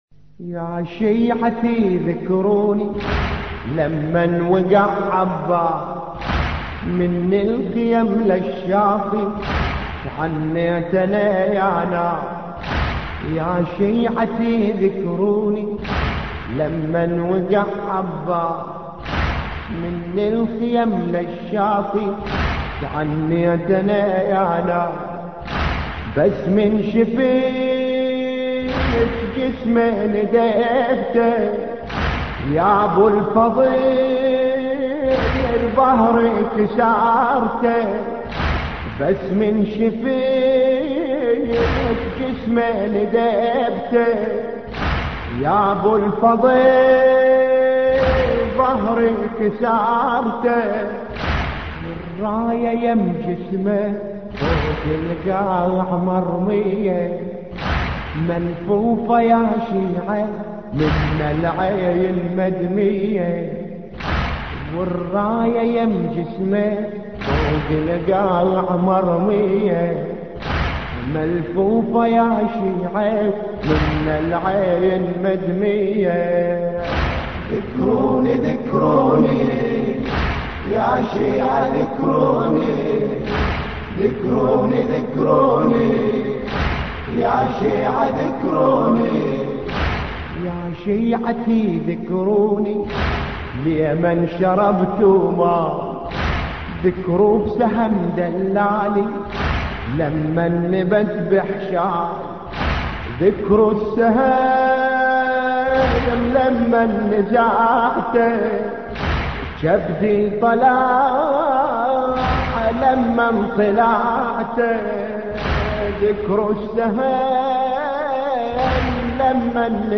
مراثي